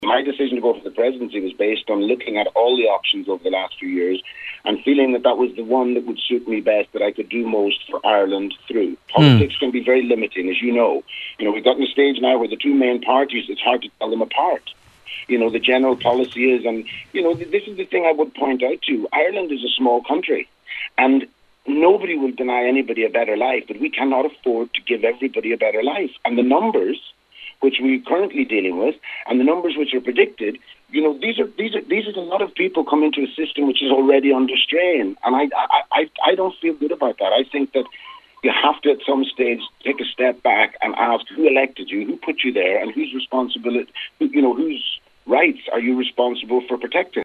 He spoke on today’s Nine Till Noon Show: